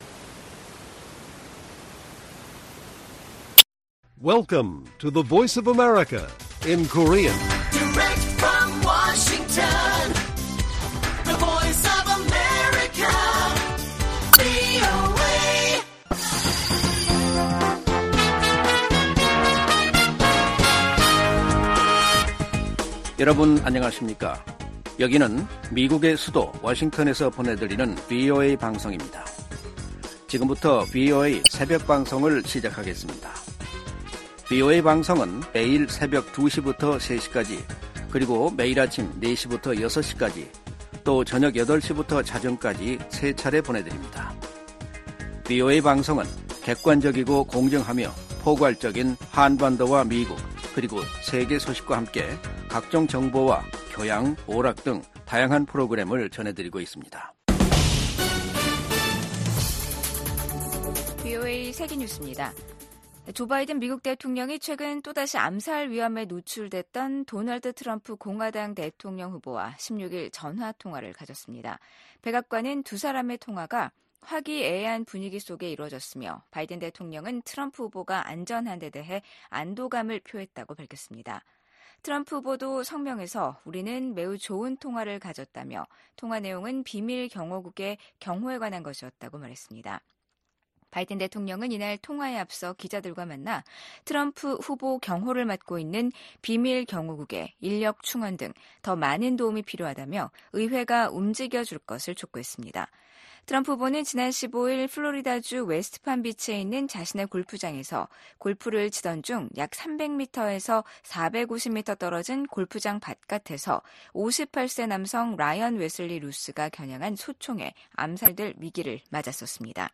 VOA 한국어 '출발 뉴스 쇼', 2024년 9월 18일 방송입니다. 스웨덴이 신종 코로나에 따른 북한의 국경 봉쇄 조치 이후 서방 국가로는 처음으로 외교관들을 북한에 복귀시켰습니다. 국제원자력기구(IAEA) 총회가 개막한 가운데 북한의 지속적인 핵 개발은 명백한 유엔 안보리 결의 위반이라고 IAEA 사무총장이 지적했습니다. 유럽연합이 최근 우라늄 농축시설을 공개한 북한에 대해 결코 핵보유국 지위를 가질 수 없다고 지적했습니다.